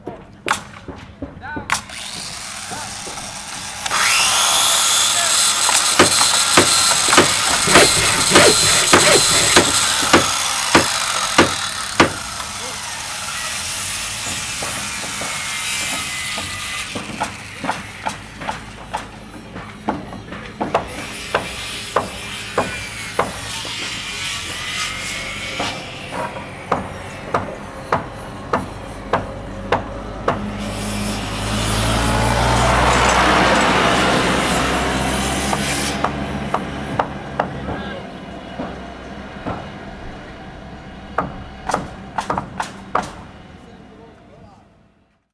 construct.ogg